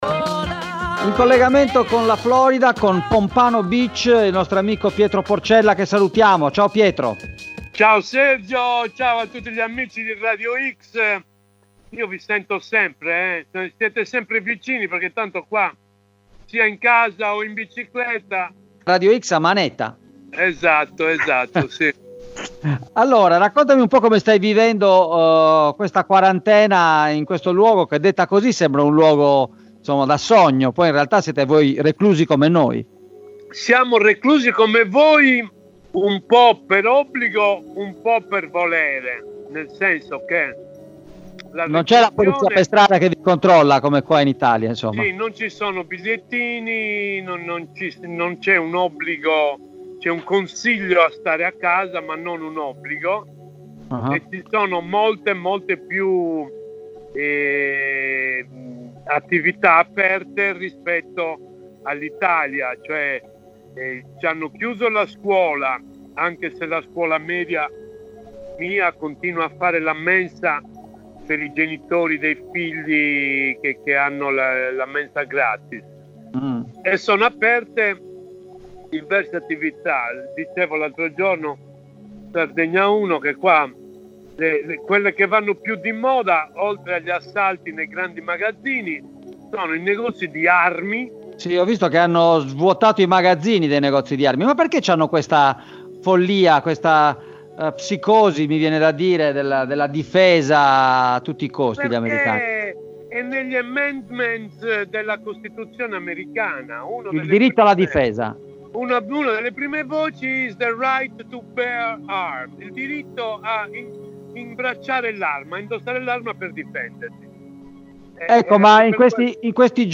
In diretta dagli U.S.A